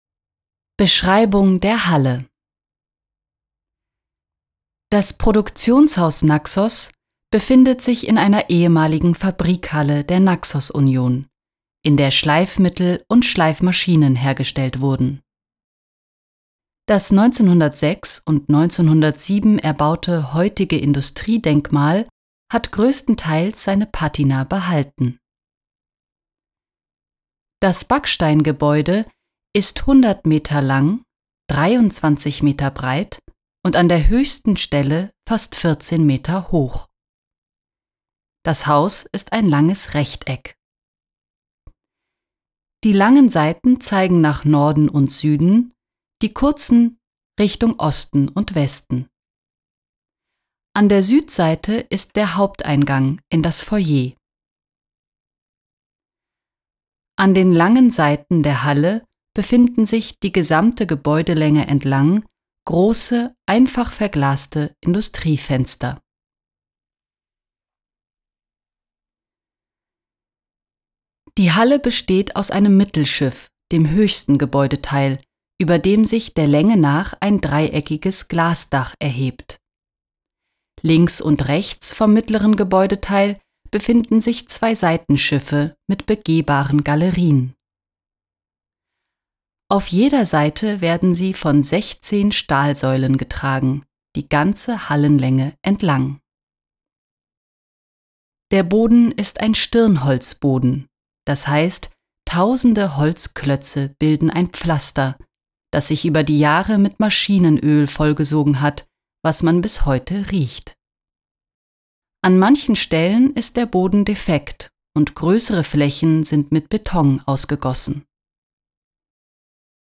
Hier finden Sie ein Audiodokument mit einer Beschreibung der Naxoshalle.
naxos-hallenbeschreibung.mp3